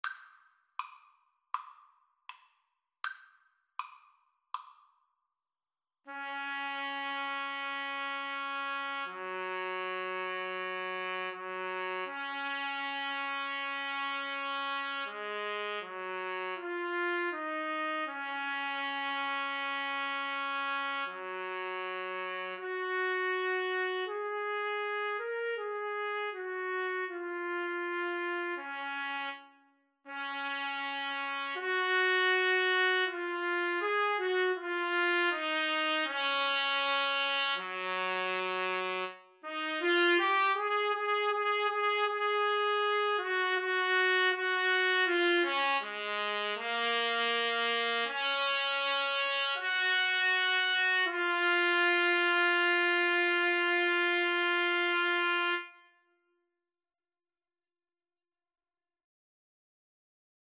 Play (or use space bar on your keyboard) Pause Music Playalong - Player 1 Accompaniment reset tempo print settings full screen
Db major (Sounding Pitch) Eb major (Trumpet in Bb) (View more Db major Music for Trumpet Duet )
Espressivo Andante